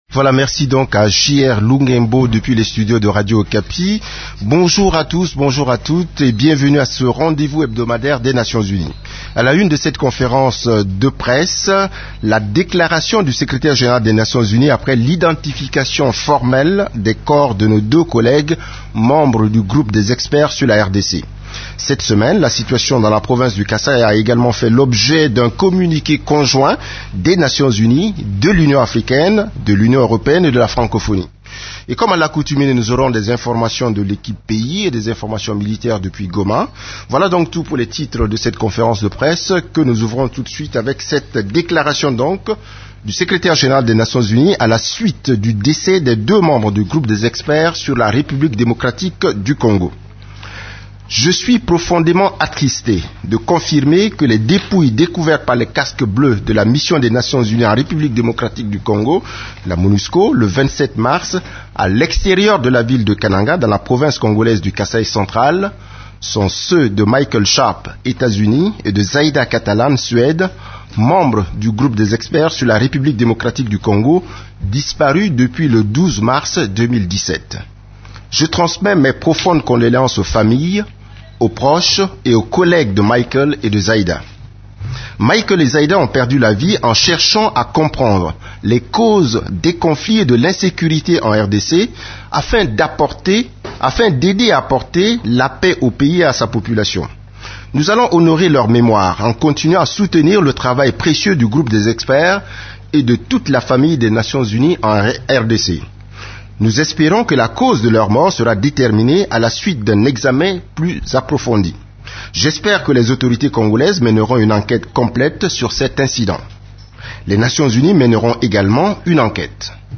Conférence de presse du 29 mars 2017
La conférence de presse hebdomadaire des Nations unies du mercredi 29 mars à Kinshasa a porté sur la situation sur les activités des composantes de la MONUSCO, des activités de l’Equipe-pays ainsi que de la situation militaire à travers la RDC.